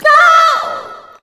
SCREAM 9  -R.wav